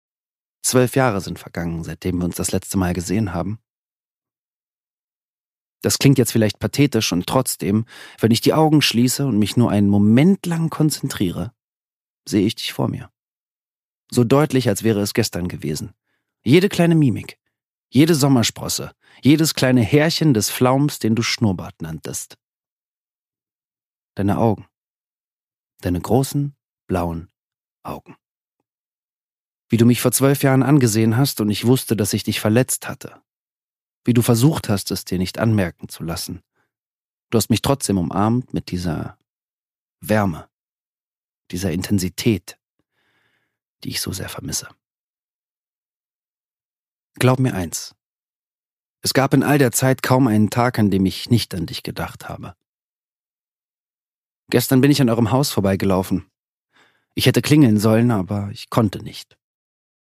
Daniel Donskoy: Brennen (Ungekürzte Lesung)
Produkttyp: Hörbuch-Download
Gelesen von: Daniel Donskoy